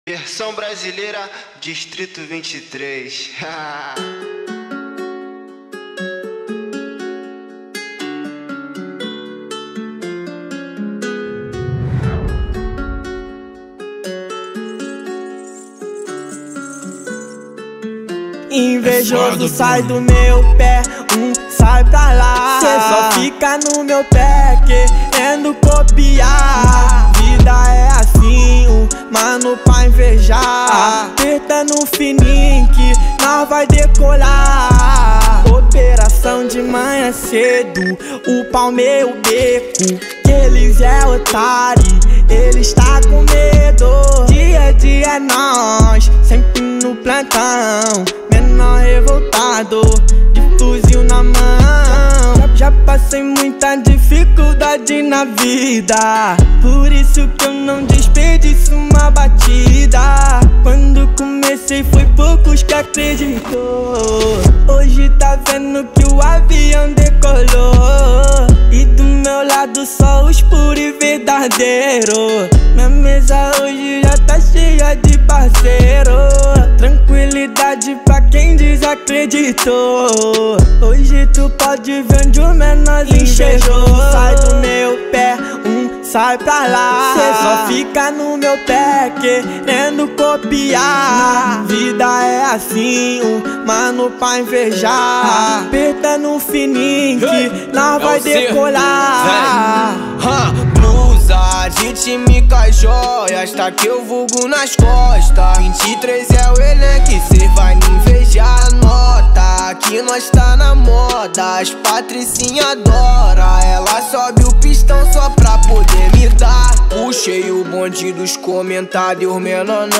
2024-11-22 08:46:45 Gênero: Trap Views